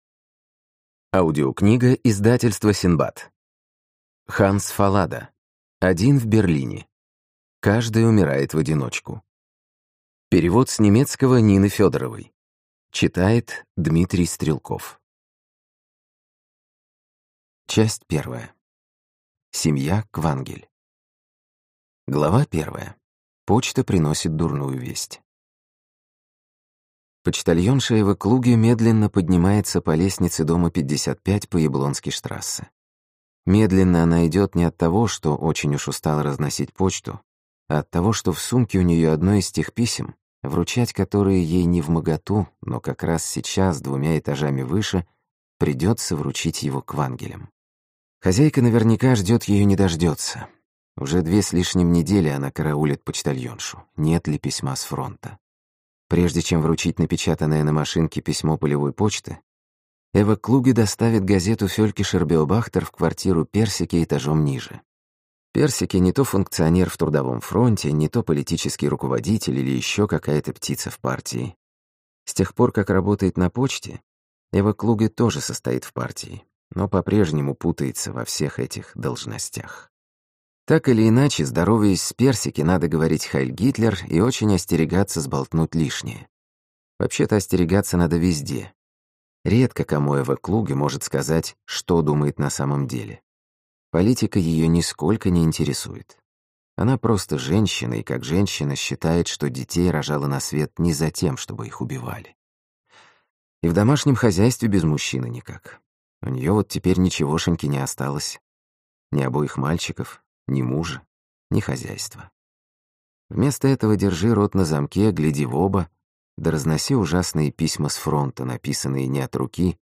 Аудиокнига Один в Берлине (Каждый умирает в одиночку) | Библиотека аудиокниг